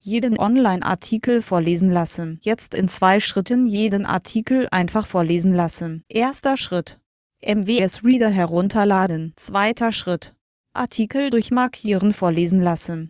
Jeden online Artikel vorlesen lassen
jeden-artikel-vorlesen-lassen.mp3